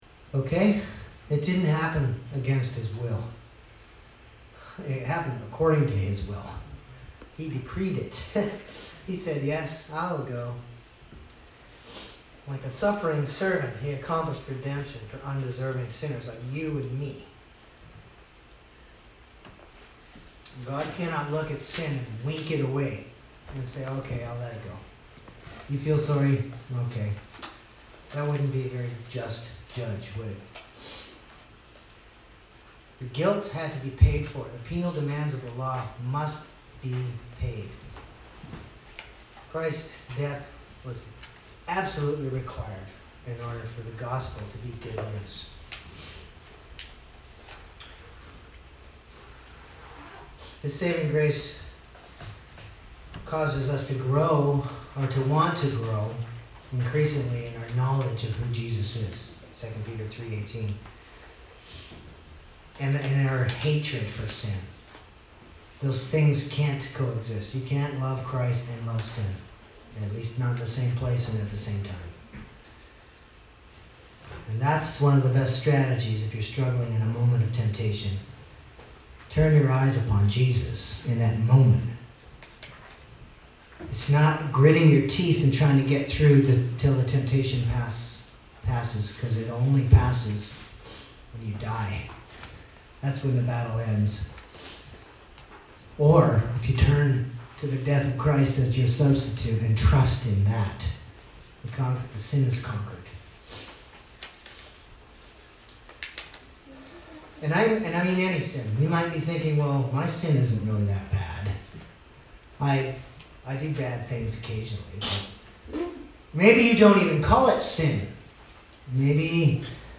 Due to Technical difficulties, this sermon was recorded and uploaded in two parts. Part 2 is the last 15 minutes of the message.